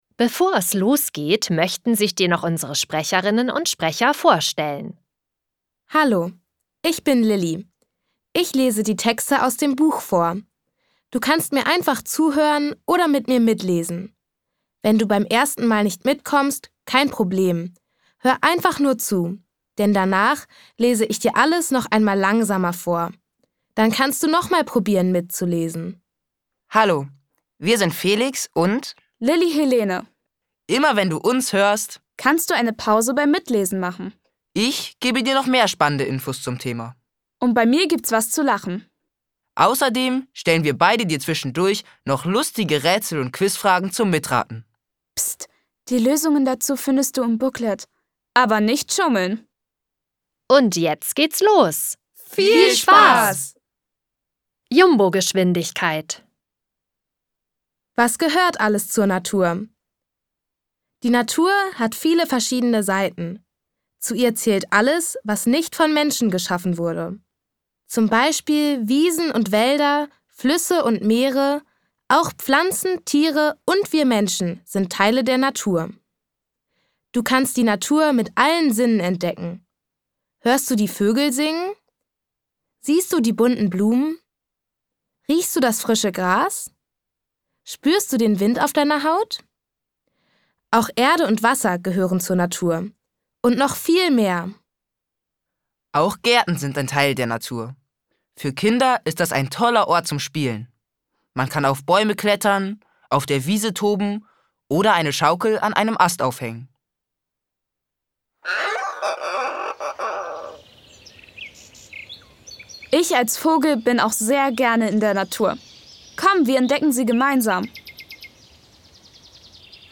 Mit diesem Hörbuch können Kinder verschiedene Lebensräume erkunden und die unterschiedlichsten Lebewesen kennenlernen. Für jede Menge Spaß sorgen Witze und spannende Rätsel.